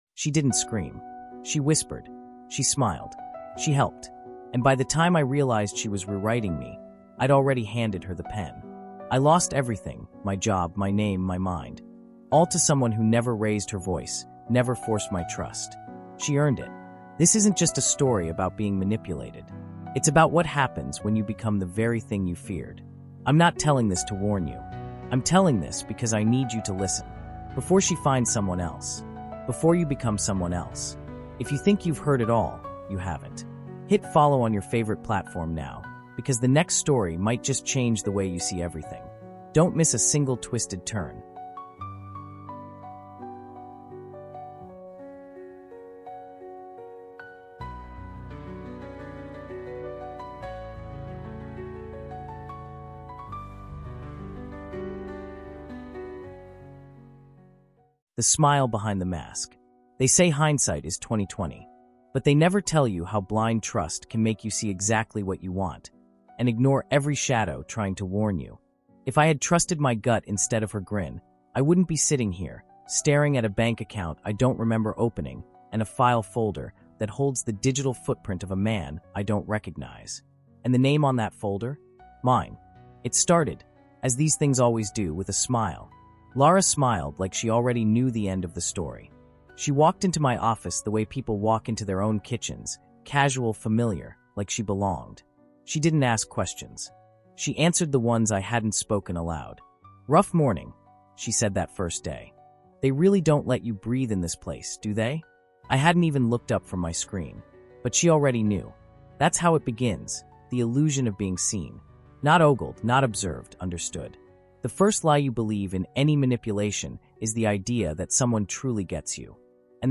This emotionally immersive, first-person audio experience peels back the layers of dark psychology, digital betrayal, and cognitive warfare, delivering a chilling lesson in how power hides behind charm — and how the deadliest manipulations come without force.